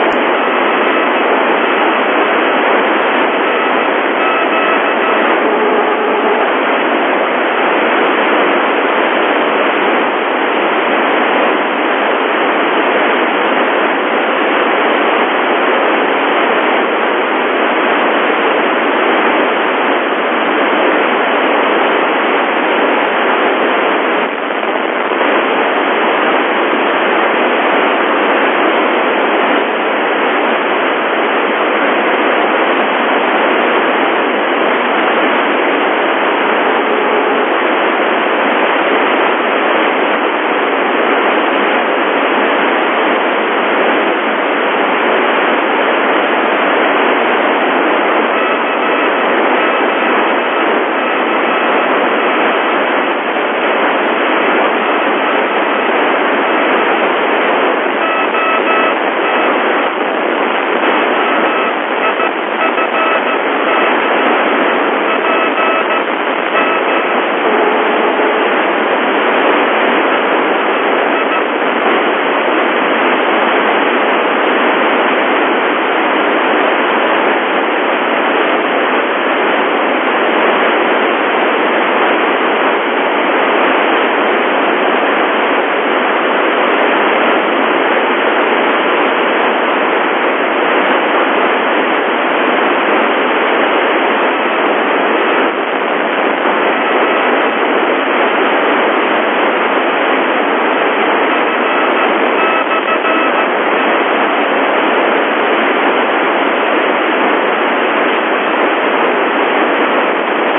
Mod F2 CW na FM